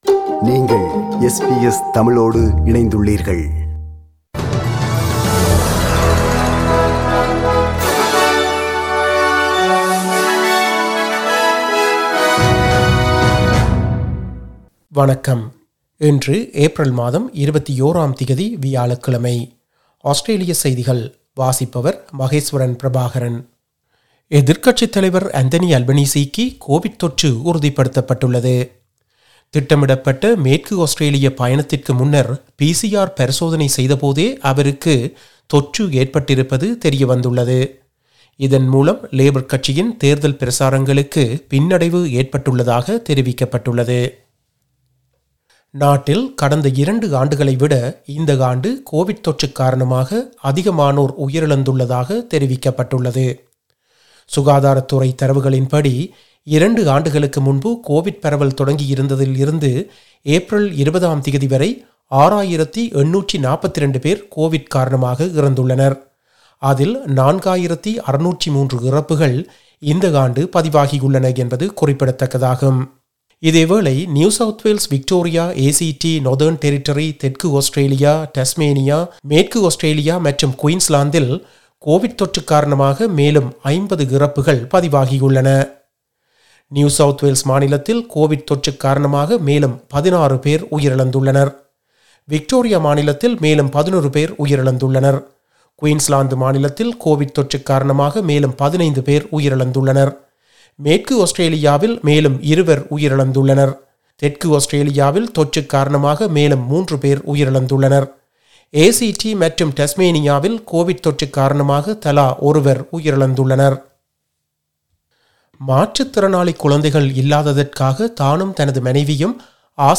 Australian news bulletin for Thursday 21 April 2022.